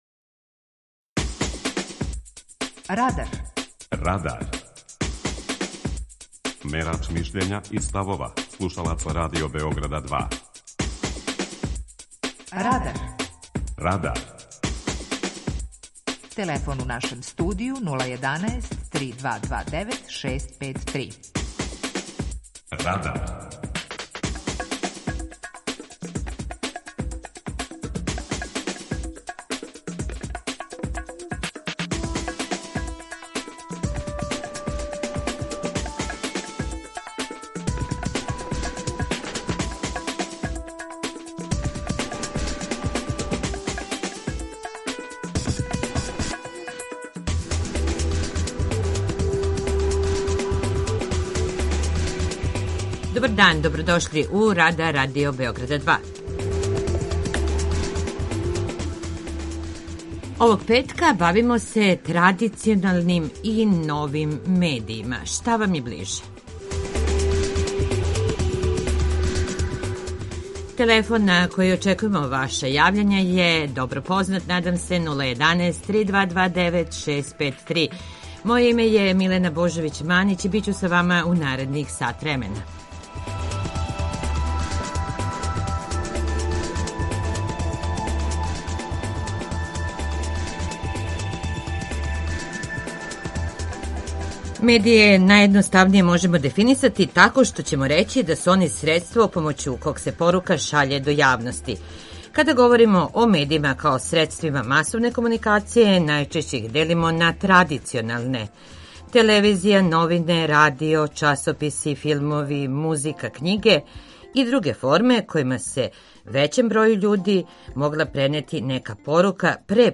Питање Радара је: Да ли су Вам ближи традиционални или нови медији? преузми : 18.56 MB Радар Autor: Група аутора У емисији „Радар", гости и слушаоци разговарају о актуелним темама из друштвеног и културног живота.